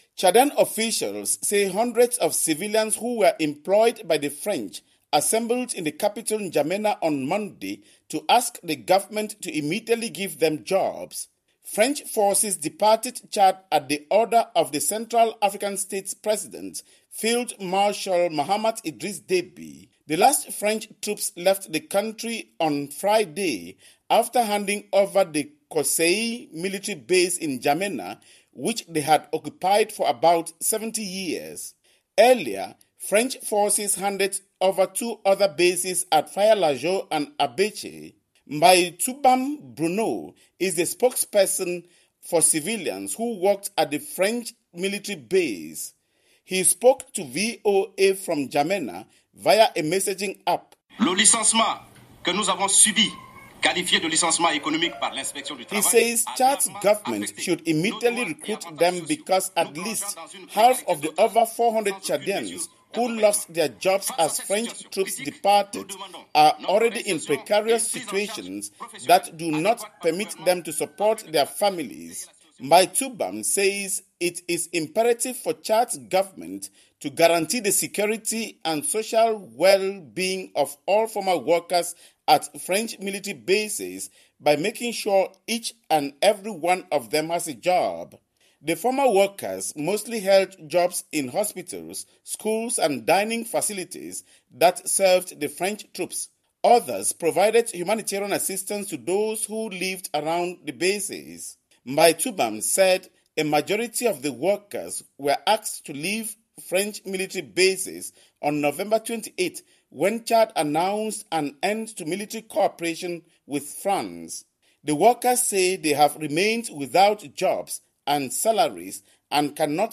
reports from neighboring Cameroon